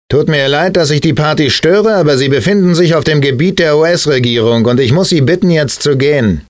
The game version of the most successful film of 1997, including the original voices from the Columbia film. The German voice-over actors for the cinema also lent their voices to their roles’ digital duplicates, thus helping to create a German version of the game which bears a strong resemblance to the movie.